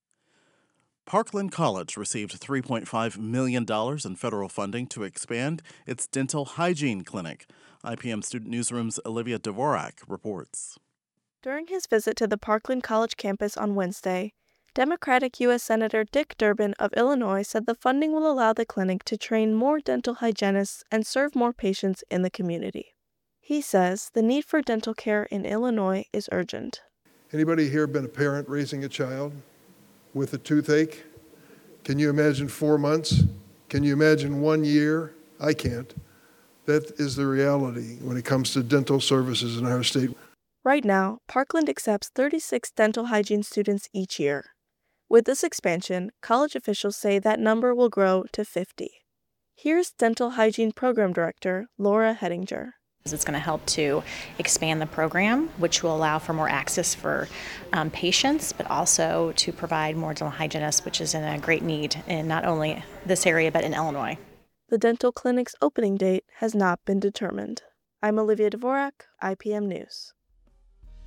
U.S. Sen. Dick Durbin (D-IL) visited Parkland College Wednesday to express his support of the expansion.
Addressing attendees at Wednesday’s press conference at the Parkland College Student Union, Durbin said: “Anybody here been a parent raising a child with a toothache? Can you imagine four months? Can you imagine one year? I can’t. That is the reality when it comes to dental services in our state.”